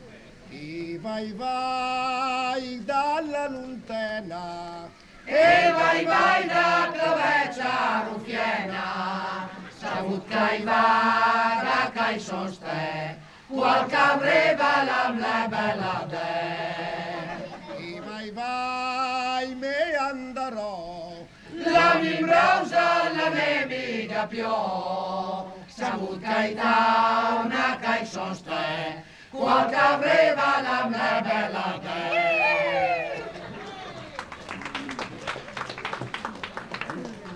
UNA SERATA CON LE MONDINE DI MEDICINA (BO, 1989) - e anche qualche canto dal loro disco